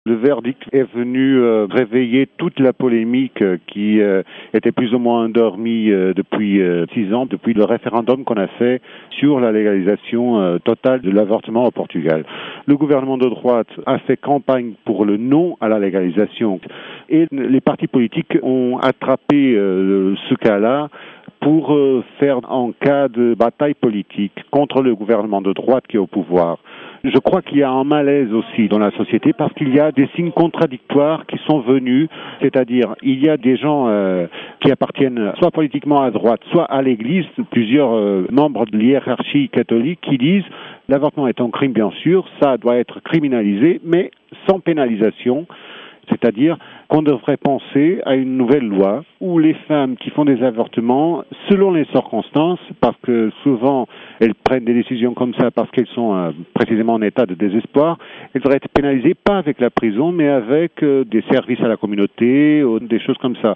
interrogé